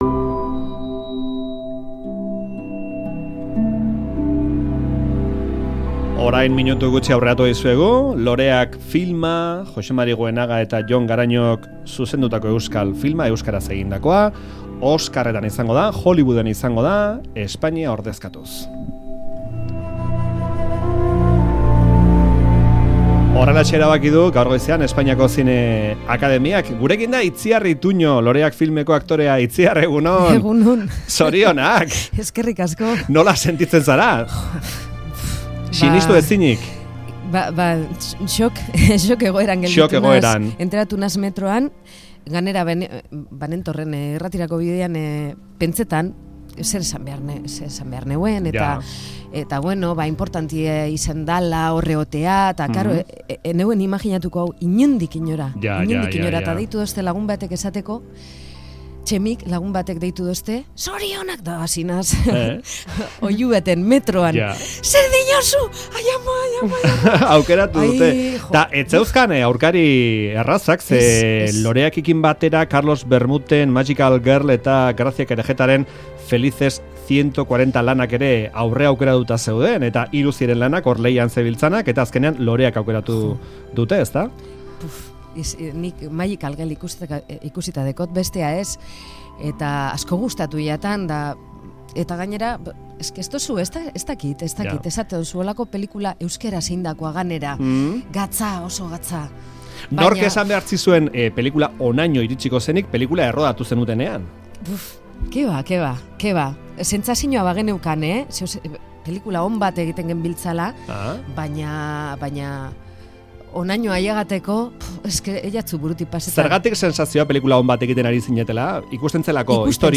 Euskadi irratiko Faktoria saioan Itziar Ituño aktorea, Loreak filma Oscarretarako izendatzeaz. Espainiaren hautagai izango da Loreak oscarretan.